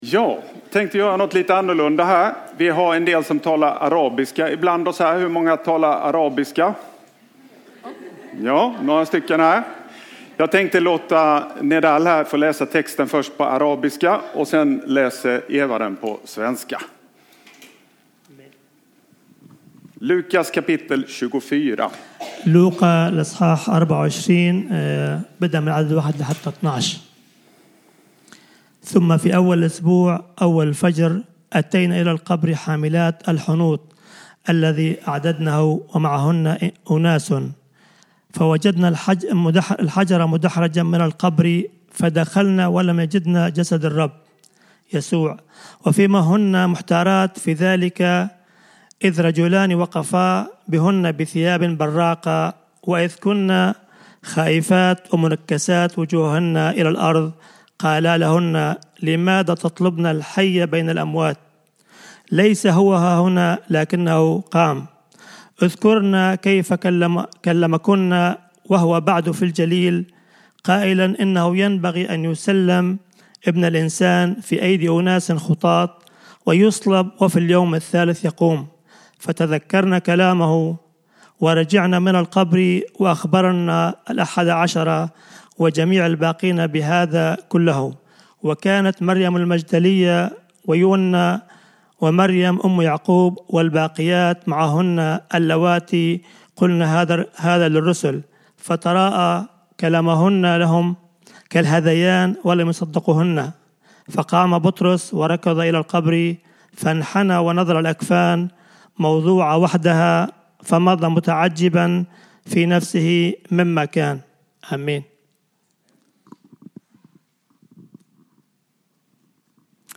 A predikan from the tema "Sommargudstjänster 2018."